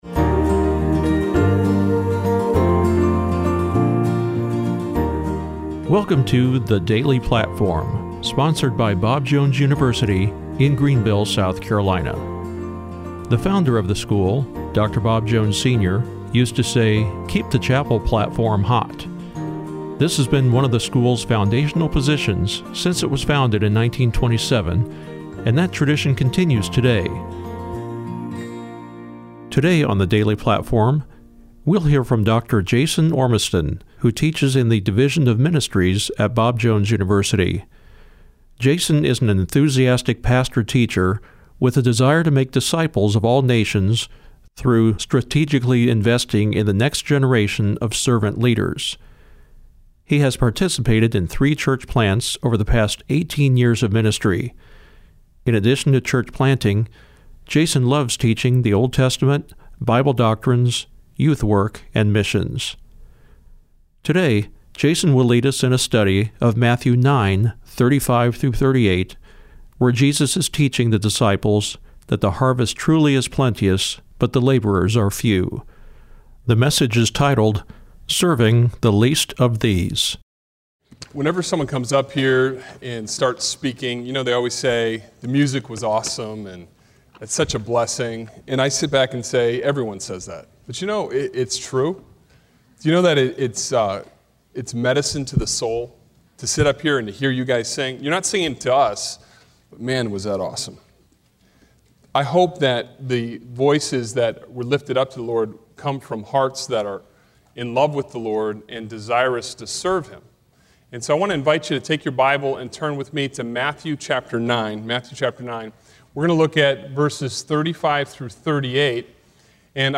preaches in Ministry Chapel – for BJU students majoring in one of the School of Religion academic programs.